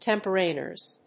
音節tem･por･ar･i･ness発音記号・読み方témpərèrinəs|-p(ə)rəri-